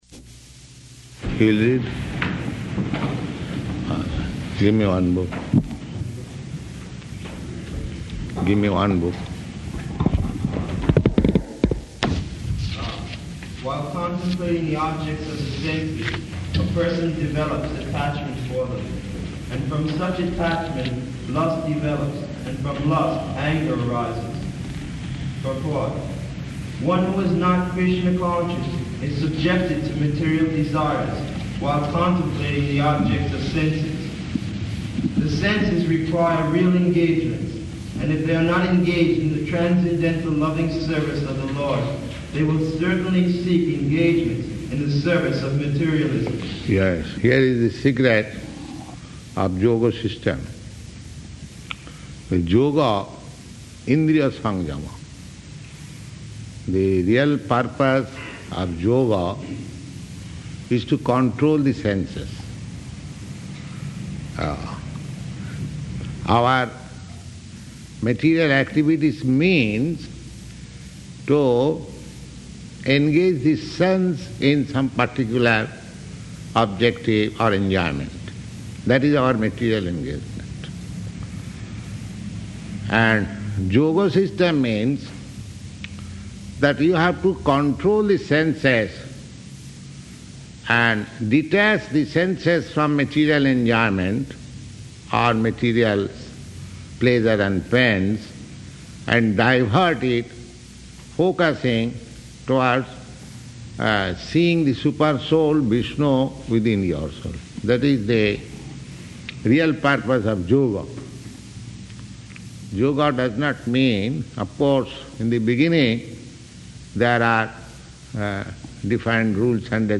Bhagavad-gītā 2.62-72 --:-- --:-- Type: Bhagavad-gita Dated: December 19th 1968 Location: Los Angeles Audio file: 681216BG-LOS_ANGELES.mp3 Prabhupāda: [ kīrtana ] [ prema-dhvani ] Thank you very much.